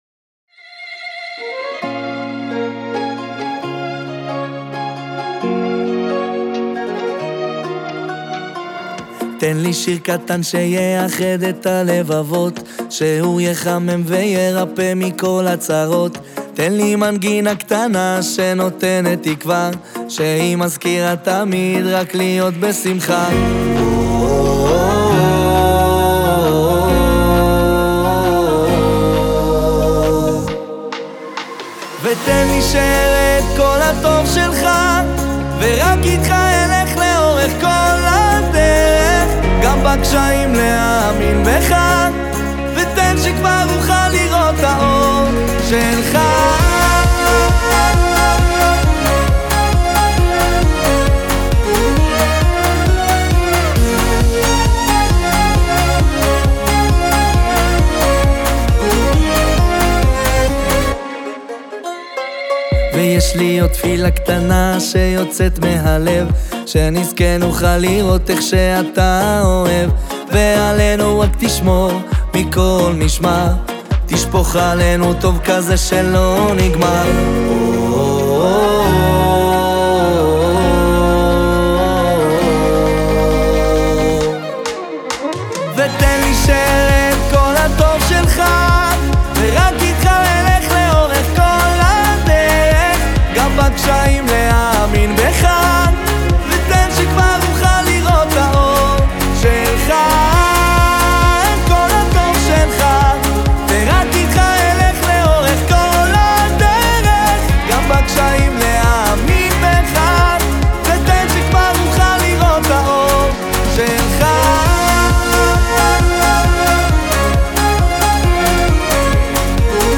הוא סינגל תקווה ותפילה, עם אווירה חורפית וסוחפת